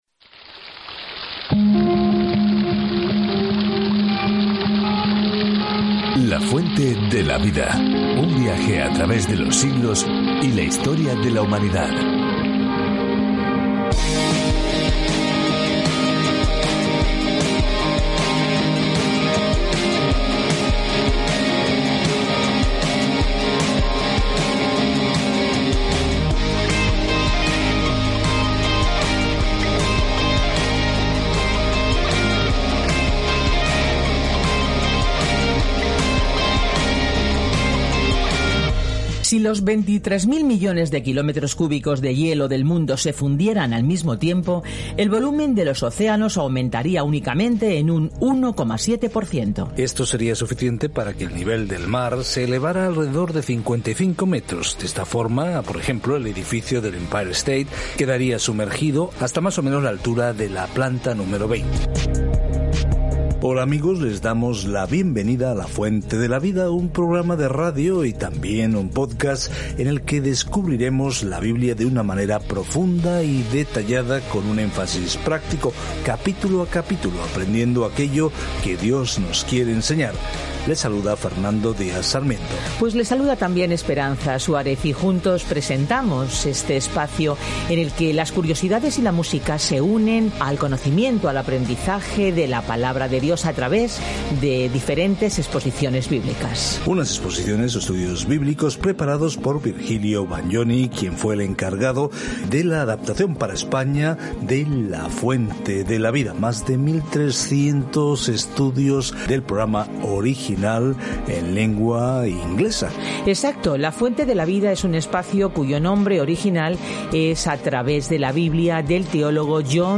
Escritura 2 CRÓNICAS 1 2 CRÓNICAS 2:1-5 Iniciar plan Día 2 Acerca de este Plan En 2 Crónicas, obtenemos una perspectiva diferente de las historias que hemos escuchado sobre los reyes y profetas del pasado de Israel. Viaje diariamente a través de 2 Crónicas mientras escucha el estudio de audio y lee versículos seleccionados de la palabra de Dios.